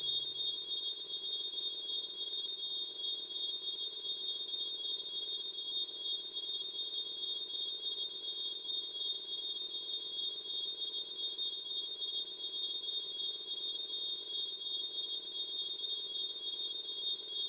electric-cicadas02
ambient bed bell chimes cicadas digital ding effect sound effect free sound royalty free Sound Effects